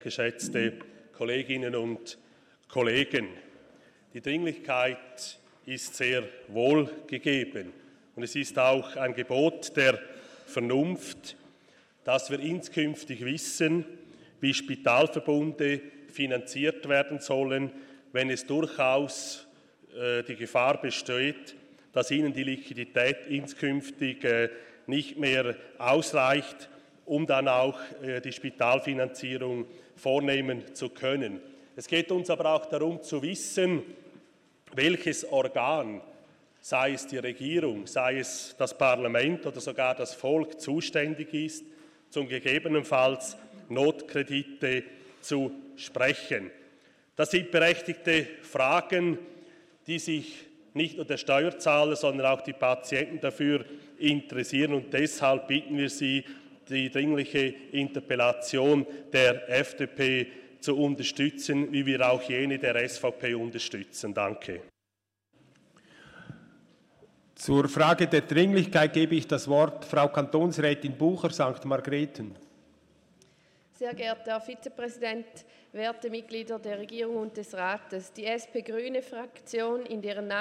24.4.2019Wortmeldung
Session des Kantonsrates vom 23. und 24. April 2019